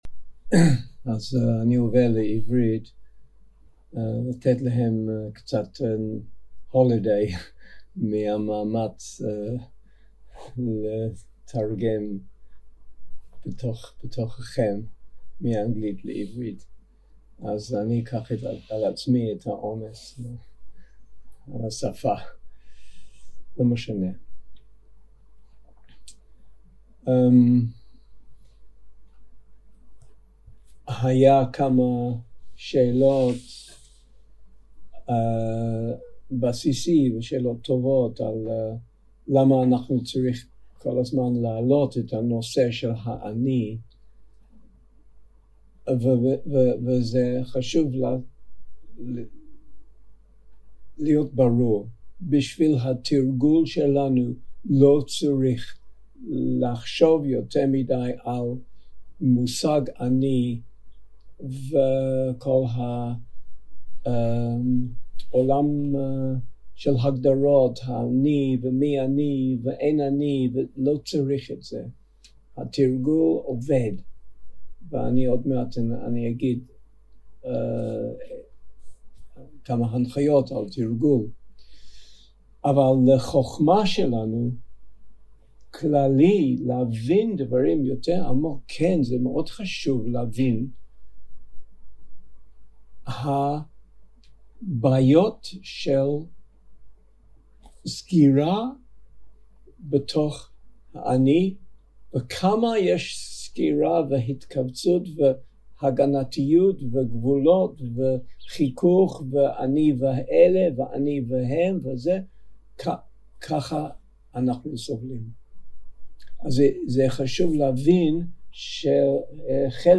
יום 3 - הקלטה 4 - בוקר - הנחיות למדיטציה - הנני - להיות זמין עם עיניים טובות 0:00 0:00 סוג ההקלטה: Dharma type: Guided meditation שפת ההקלטה: Dharma talk language: English